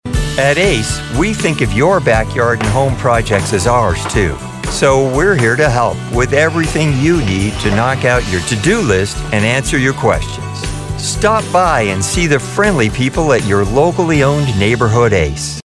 Ace Hardware/ Friendly, Approachable, Trustworthy
Middle Aged